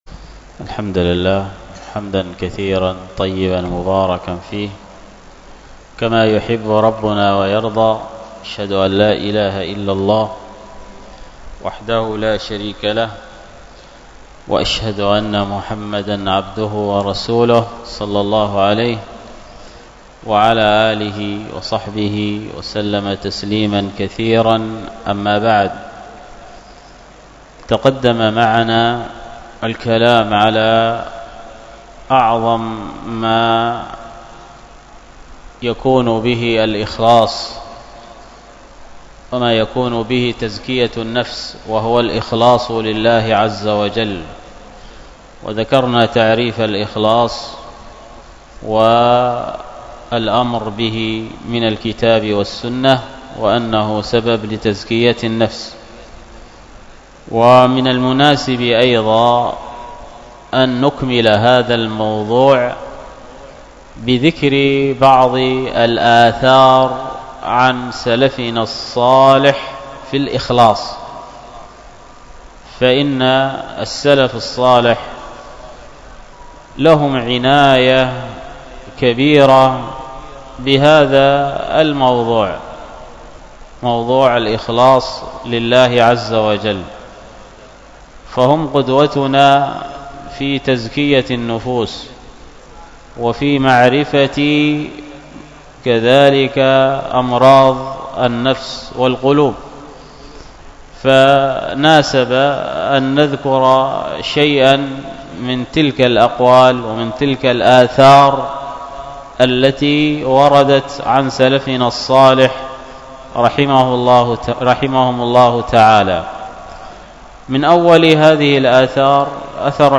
الدرس في كتاب الصلاة 33، ألقاها